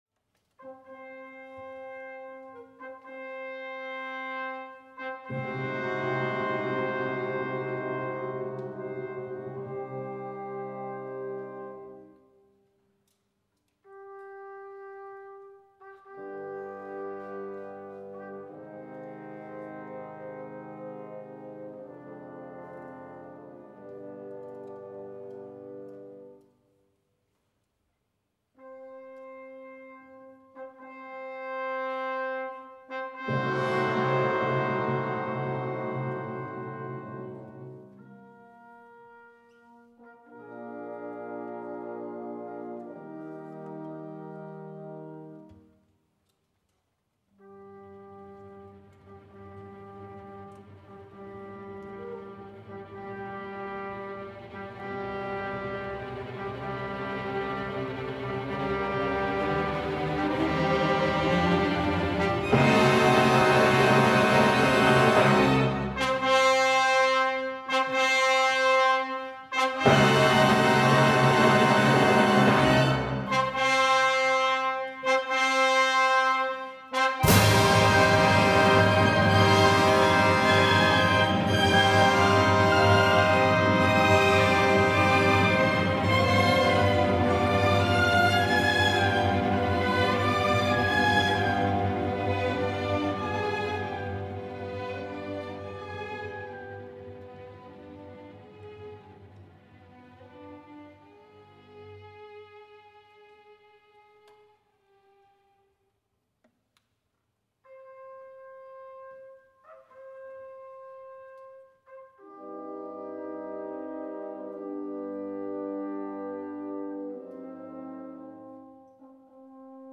Preludio (Ouvertüre)
15_preludio_ouvertuere.mp3